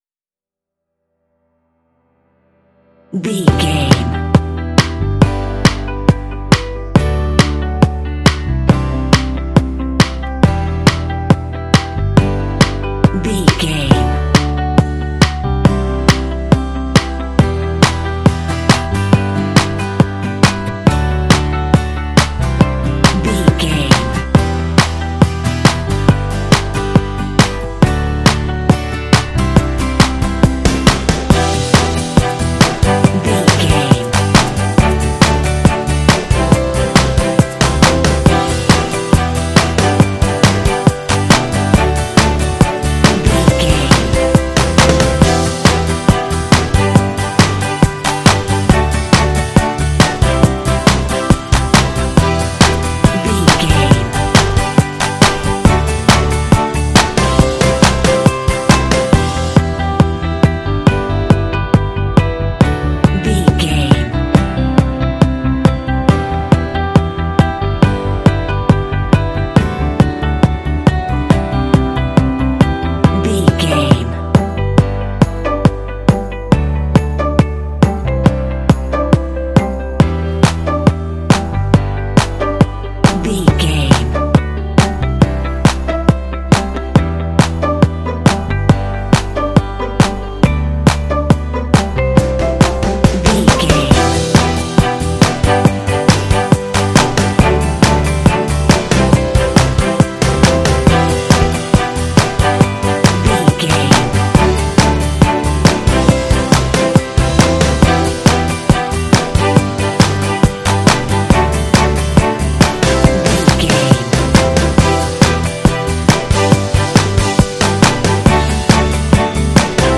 Ionian/Major
positive
optimistic
happy
inspirational
strings
orchestra
percussion
bass guitar
brass
horns
alternative rock